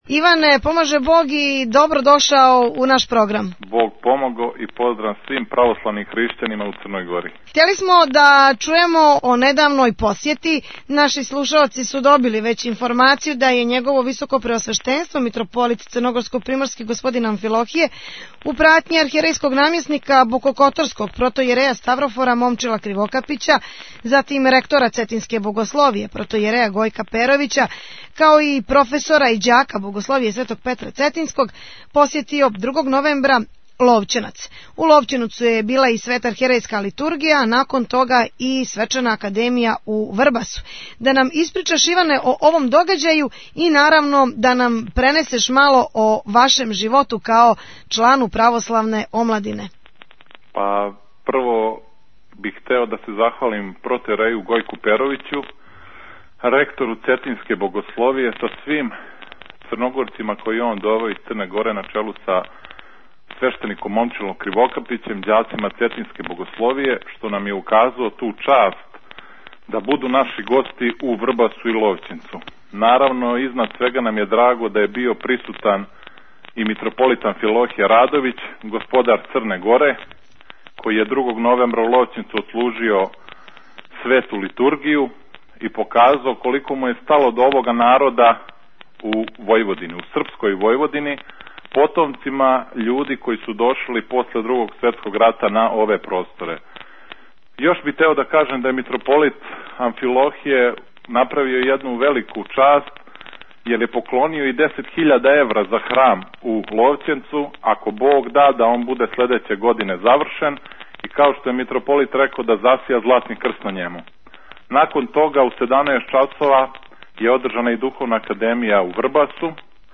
Актуелни разговори